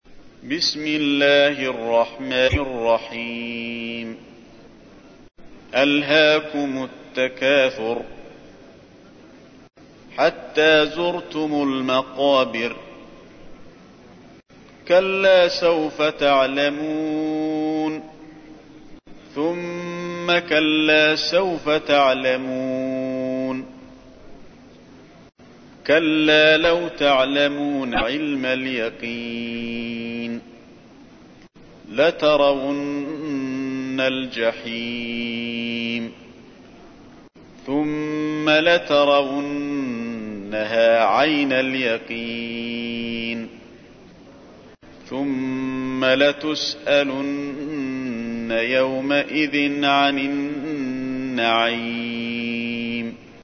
تحميل : 102. سورة التكاثر / القارئ علي الحذيفي / القرآن الكريم / موقع يا حسين